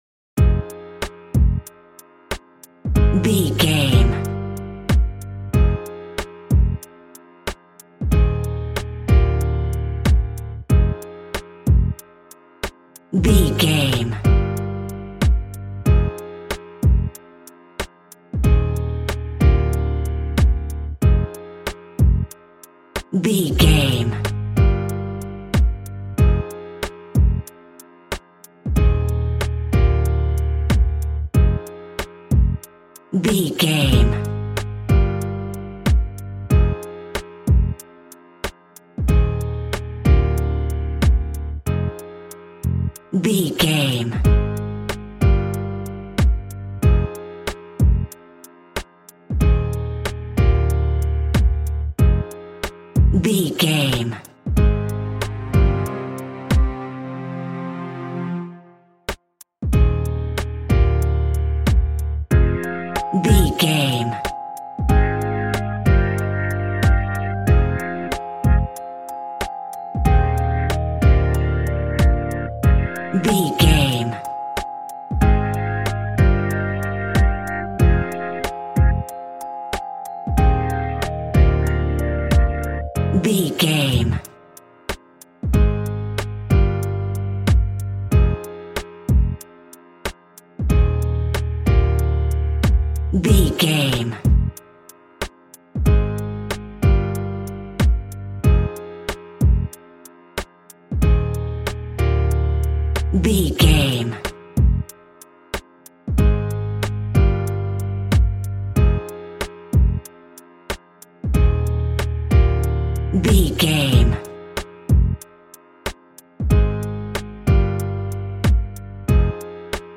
Aeolian/Minor
Funk
electronic
drum machine
synths